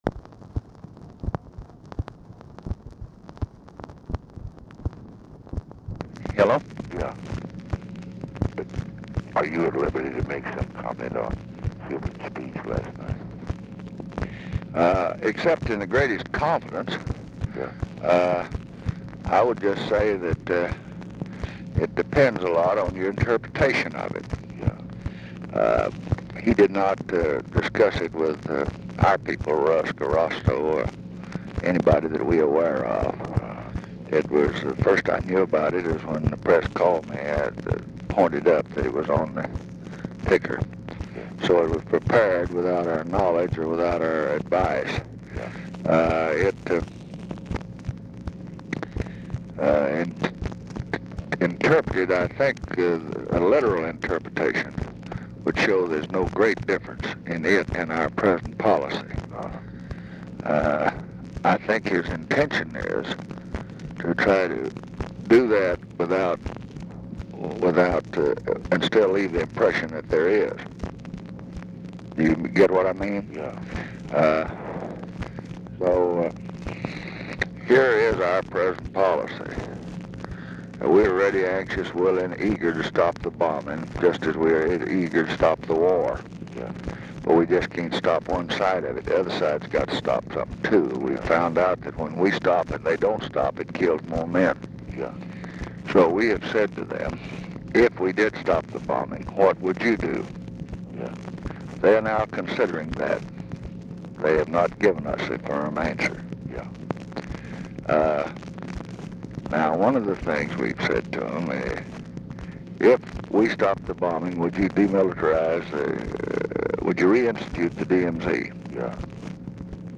Telephone conversation # 13501, sound recording, LBJ and EVERETT DIRKSEN, 10/1/1968, 10:31AM
Format Dictation belt
Location Of Speaker 1 Mansion, White House, Washington, DC